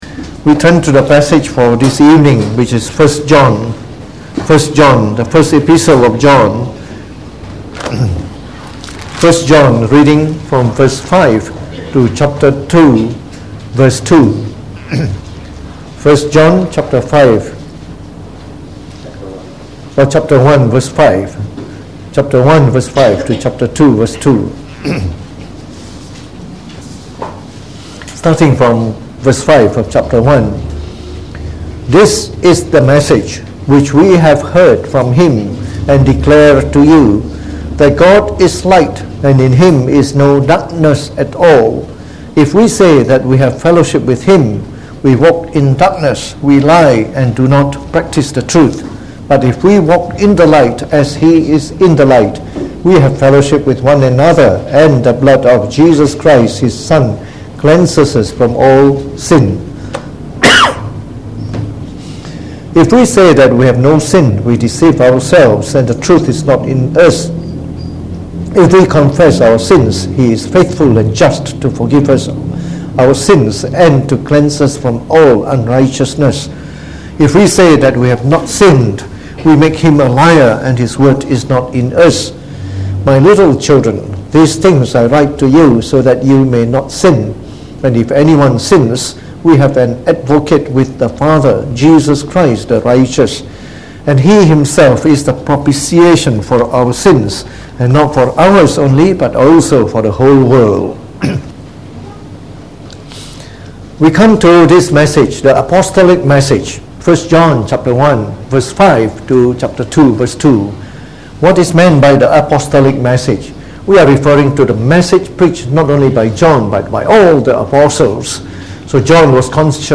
Preached on the 15th of Sept 2019.